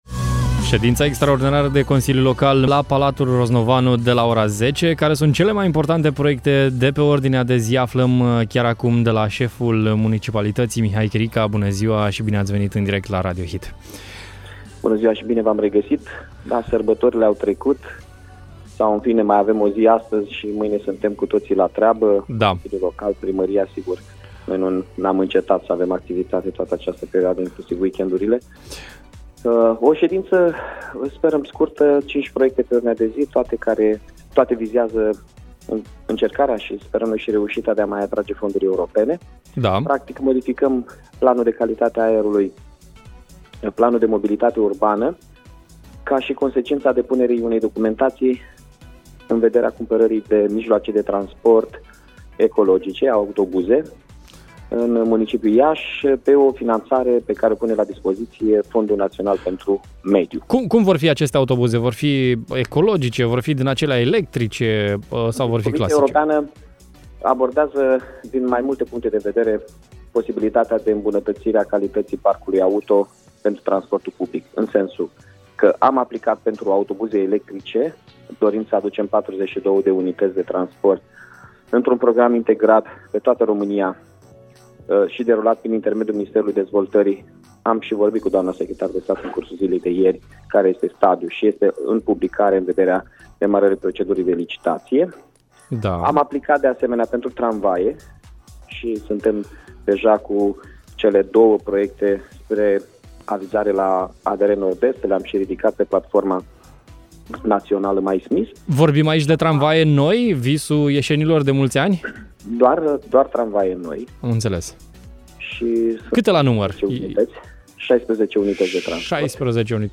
Astăzi de la 10, va avea loc o sedință extraordinară a consiliului local Iași. Care sunt subiectele de pe ordinea de zi am aflat chiar de la primarul Iașului, Mihai Chirica: